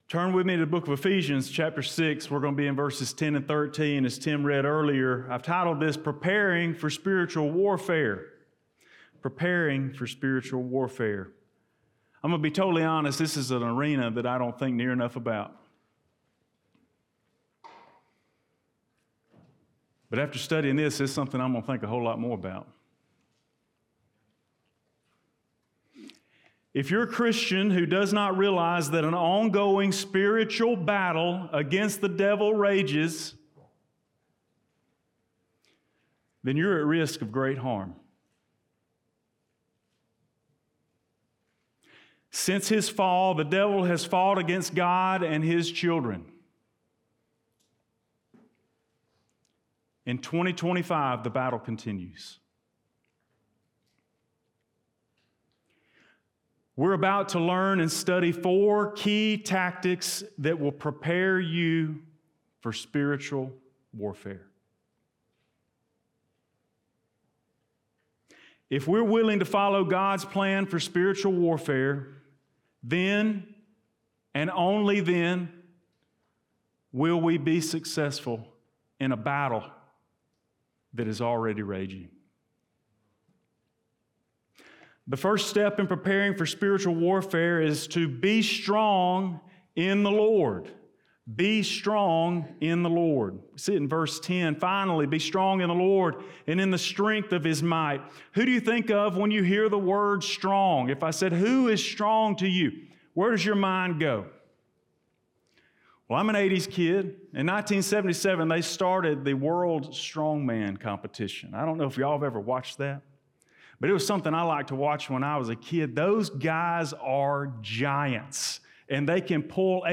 Blount Springs Baptist Church Sermons